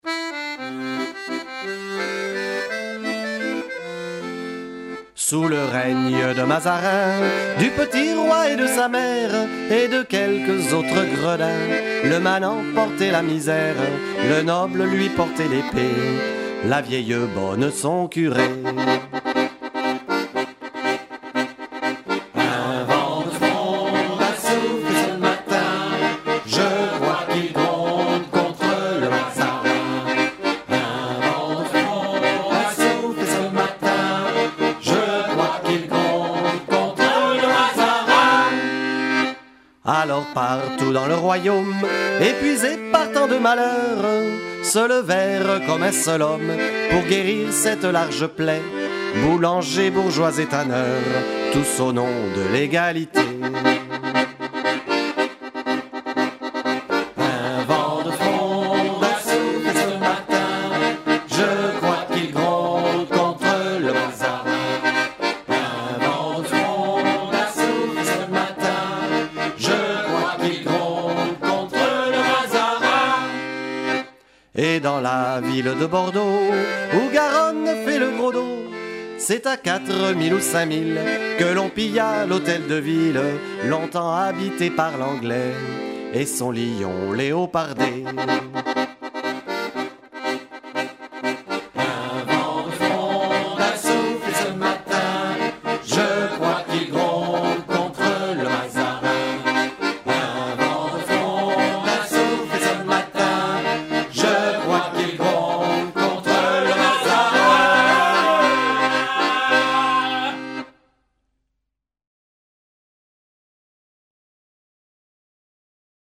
Musiques composées à l’accordéon, jouées et chantées en direct par les comédiens